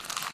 paper_scrunch_1_quieter_shorter.ogg